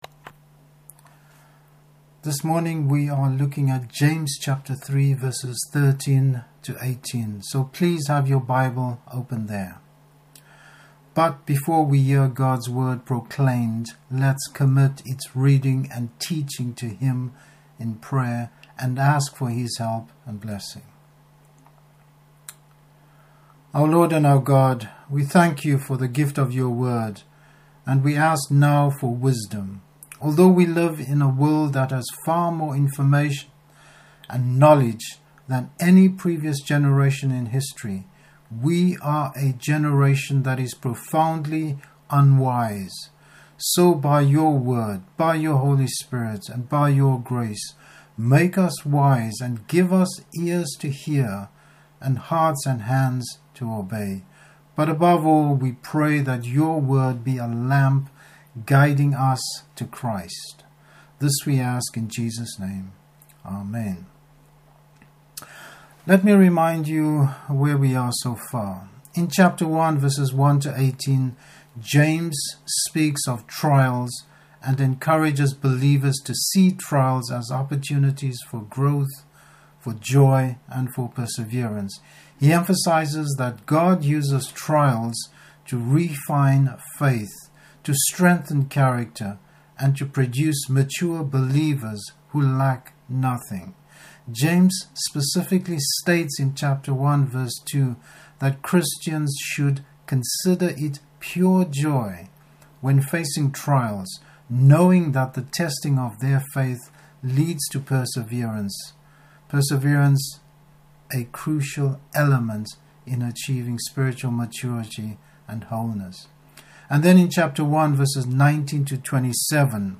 Passage: James 3:13-18. Service Type: Morning Service Godly wisdom.